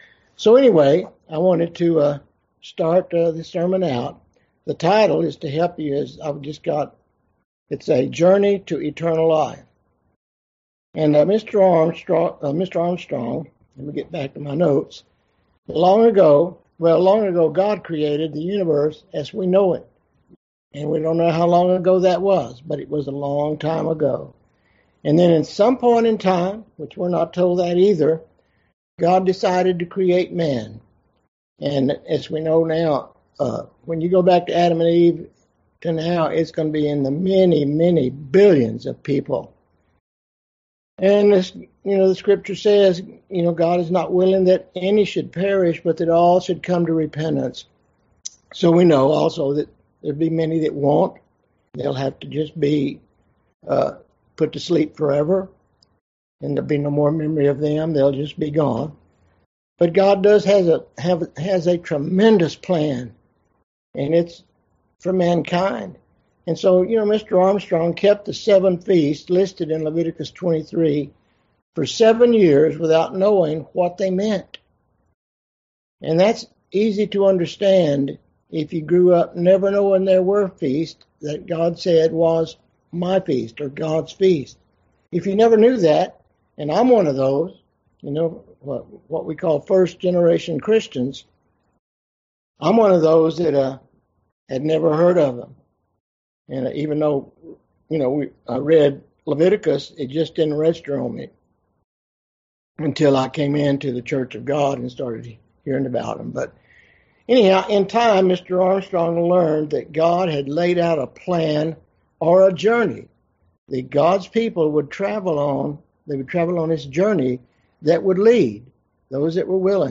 God gave us 7 Festivals that teach us the path or journey that leads to eternal life. In this sermon we will travel part of that path from Passover to Pentecost.